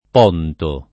p0nto] s. m. — voce poet. per «mare» — sim. il top. m. stor.